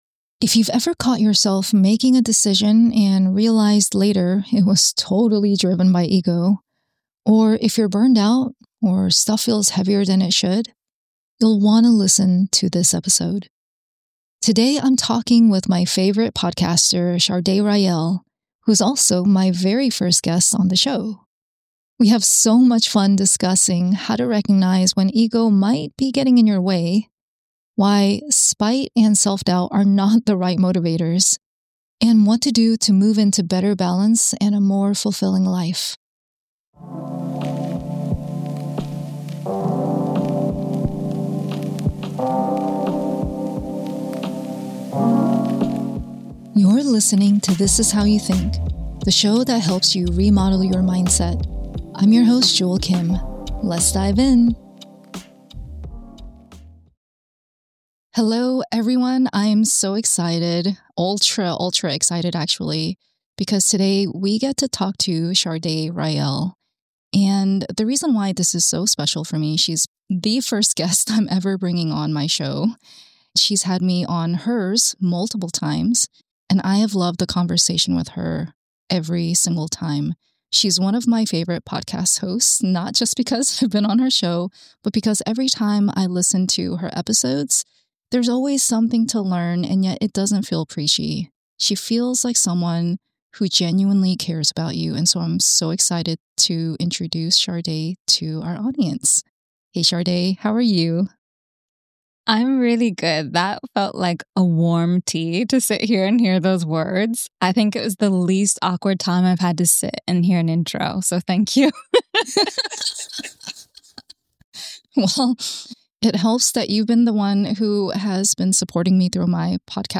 It’s fun, honest, a little spicy in places—and full of moments that will make you pause and reflect.